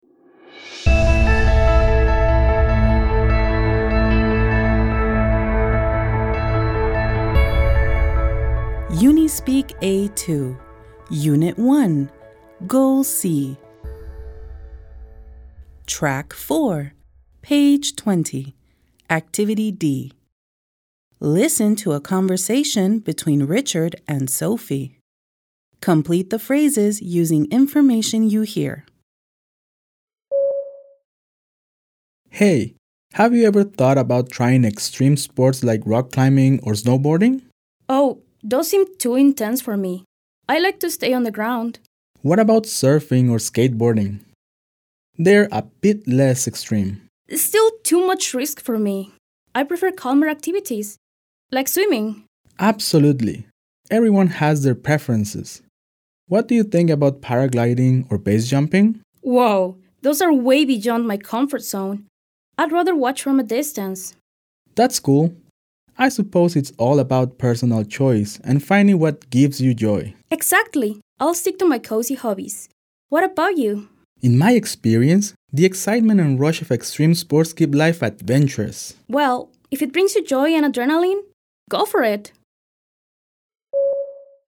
Listen to a conversation between Richard and Sophie. Complete the phrases using information you hear.